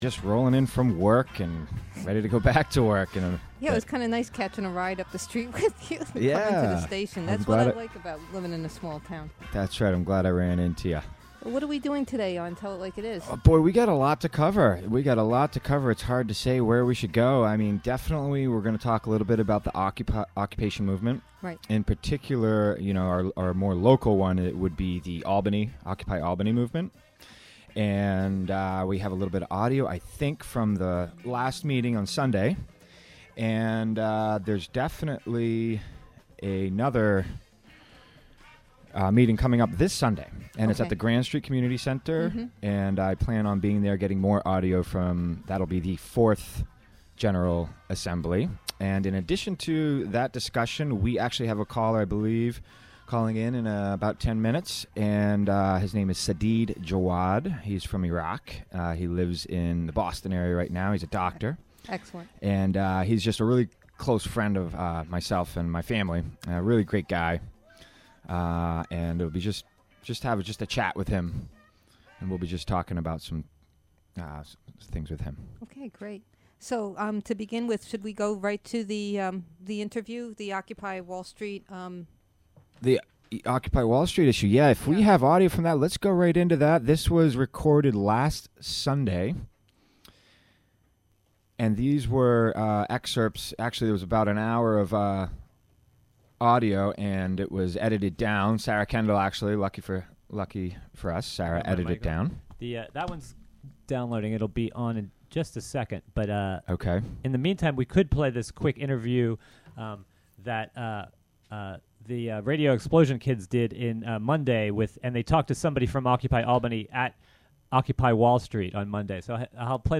Interview with Iraqi now living in Boston.